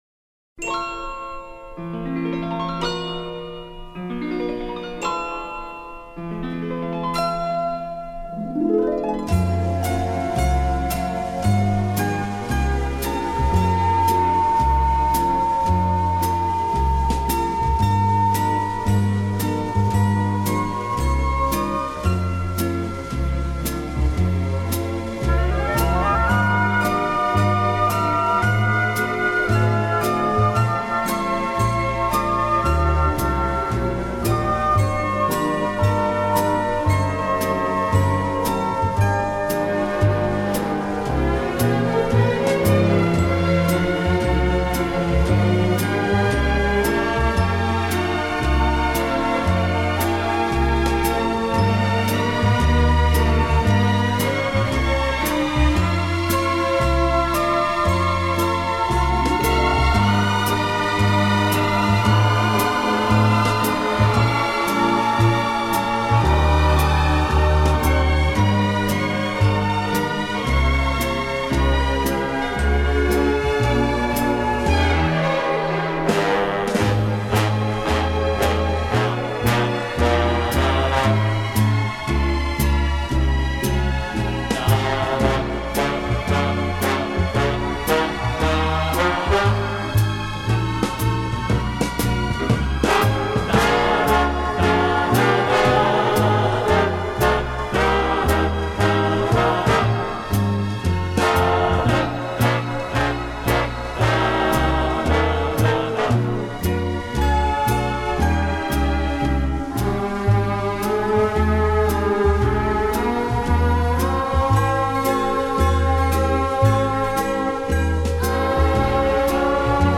Здесь оркестровый авторский вариант.